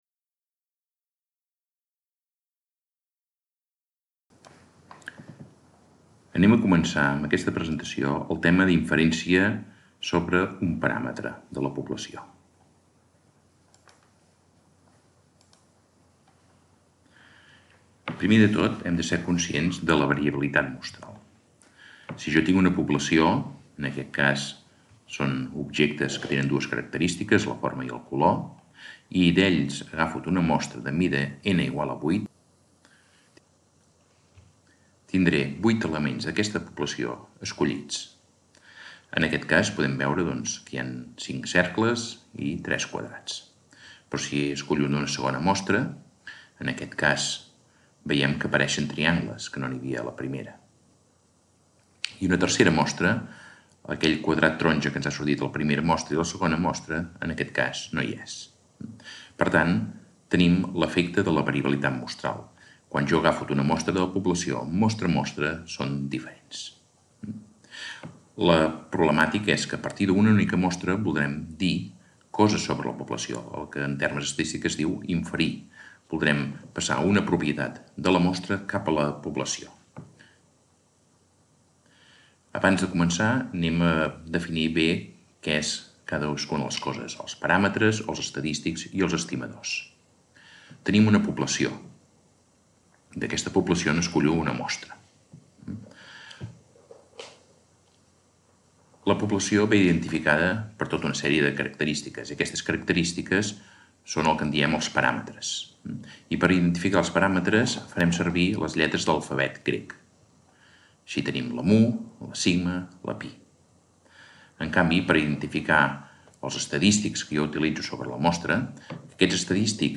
Lesson from the Theory of Statistics subject on the inference of a parameter